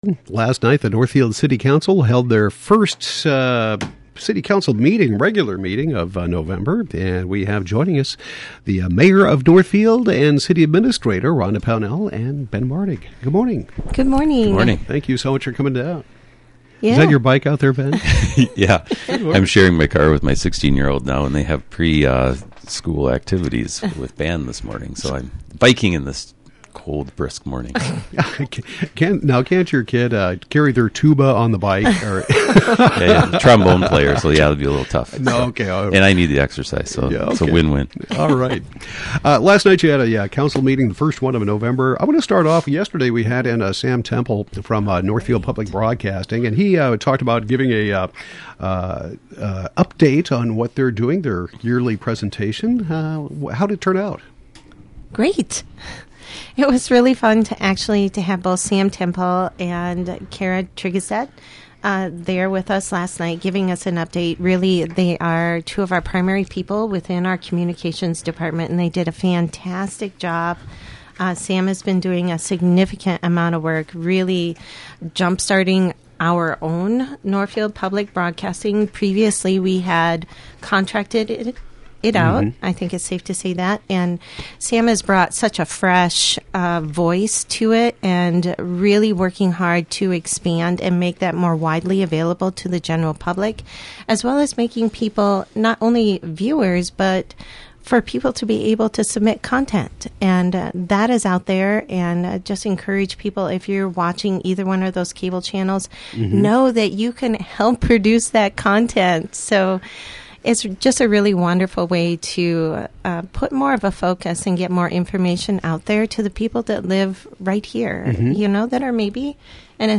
Northfield Mayor Rhonda Pownell and City Administrator Ben Martig discuss the November 2 City Council meeting.